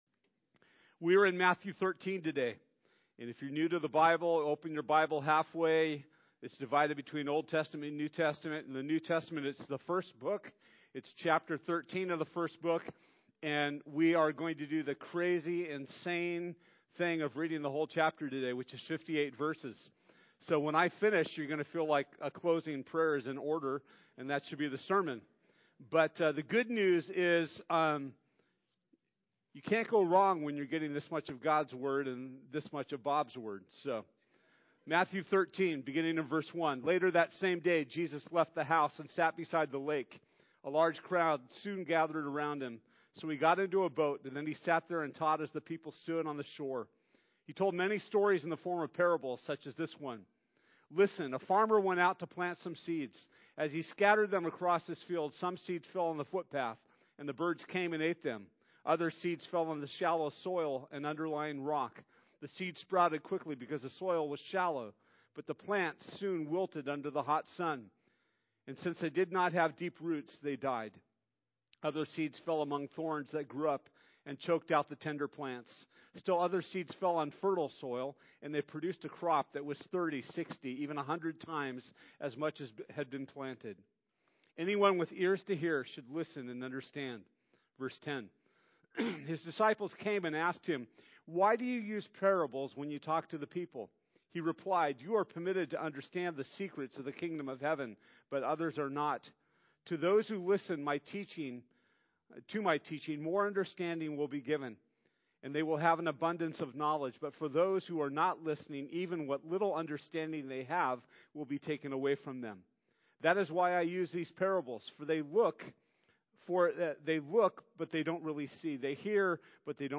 Matthew 13:1-58 Service Type: Sunday This Sunday